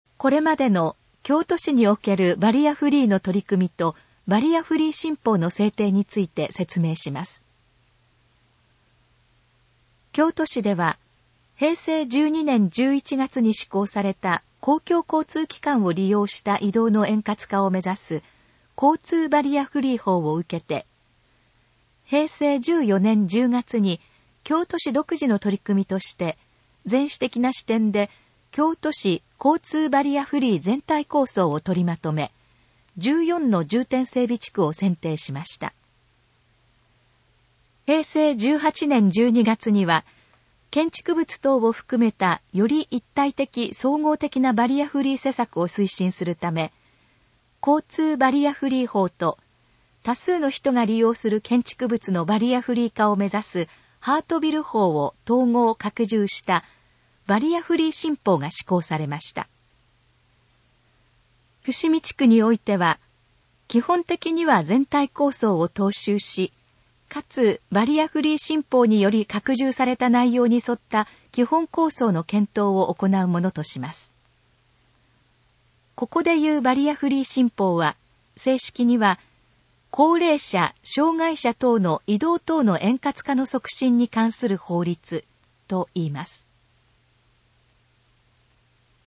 このページの要約を音声で読み上げます。
ナレーション再生 約349KB